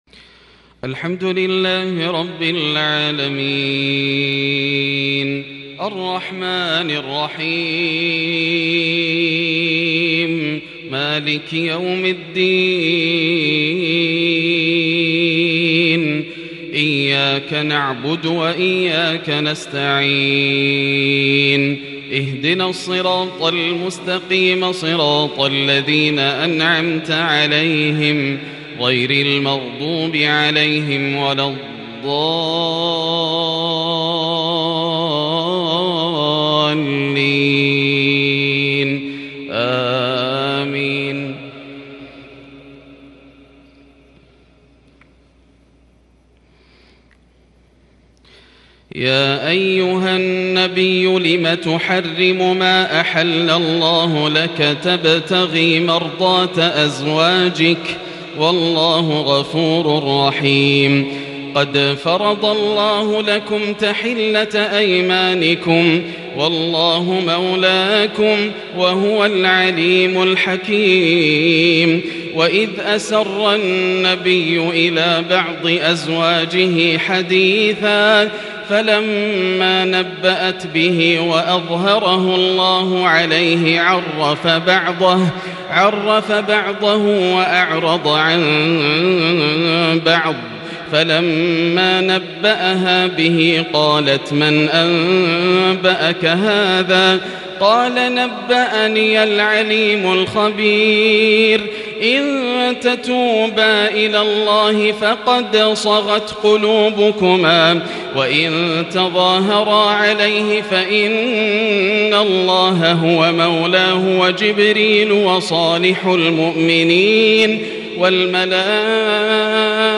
صلاة فجر 28 صفر١٤٤٢هـ : لسورتي التحريم والتكوير | Fajr prayer from Surat -AtTahrim and Surat AtTakwir | 15/10/2020 > 1442 🕋 > الفروض - تلاوات الحرمين